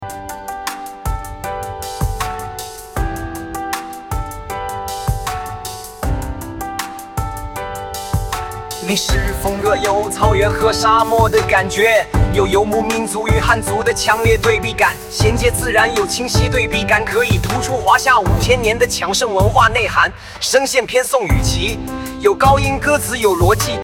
历史风格有草原和沙漠的感觉，有游牧民族与汉族的强烈对比感，衔接自然有清晰对比感可以突出华夏五千年的强盛文化内涵，声线偏宋雨琦，有高音，歌词有逻辑